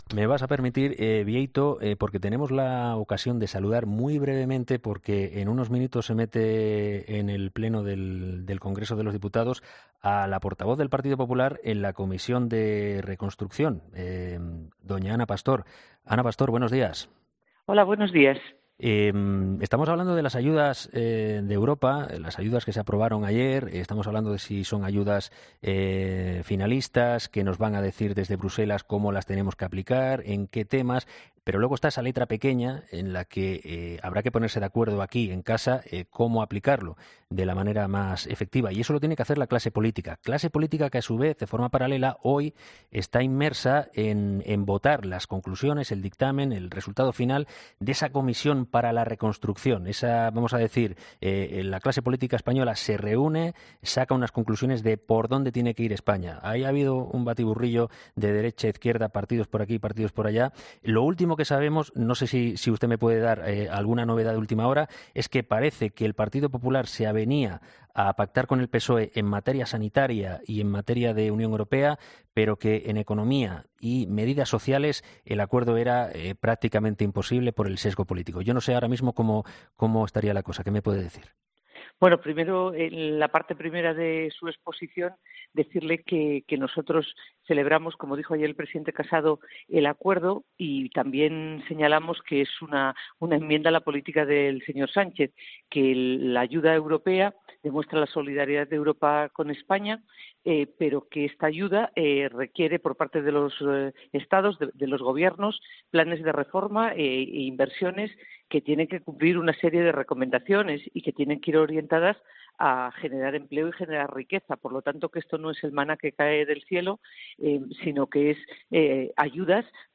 La vicepresidenta segunda del Congreso y portavoz del PP en la Comisión de Reconstrucción, Ana Pastor, ha hablado con nosotros en 'Herrera en COPE'...